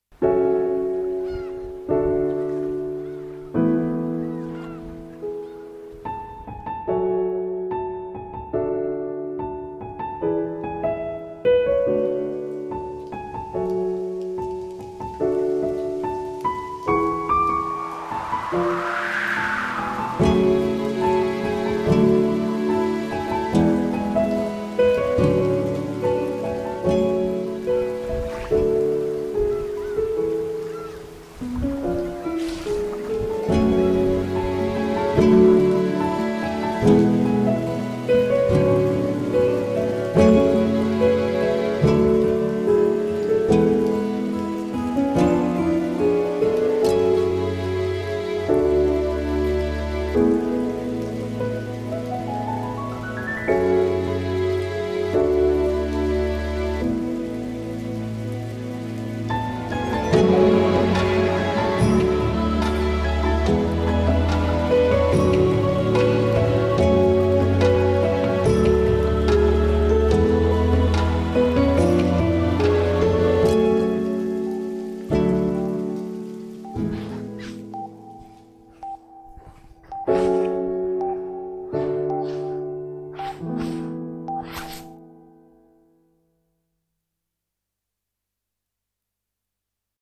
CM風声劇「ねぇ、フリューゲル」